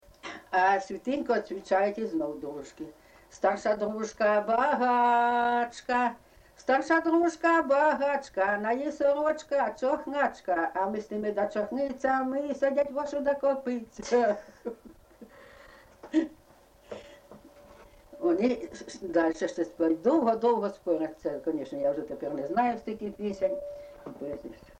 ЖанрВесільні
Місце записус. Курахівка, Покровський район, Донецька обл., Україна, Слобожанщина